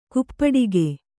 ♪ kuppaḍige